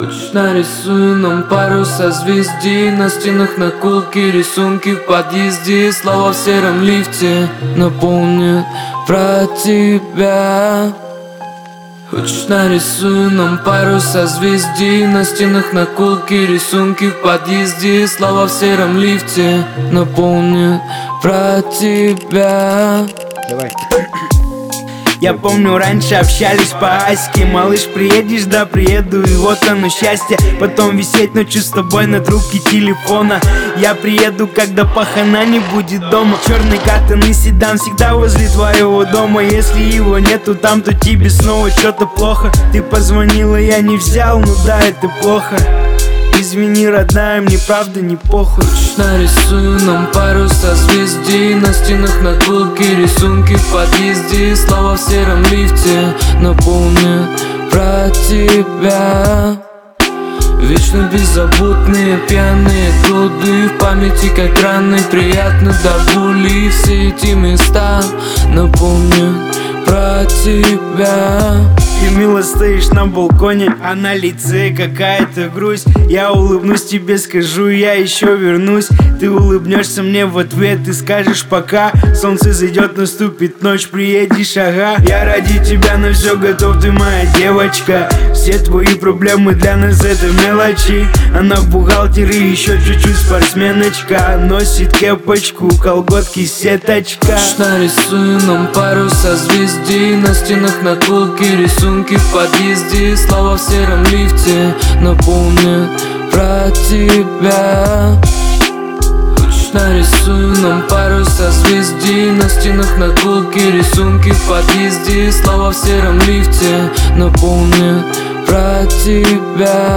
Дабстеп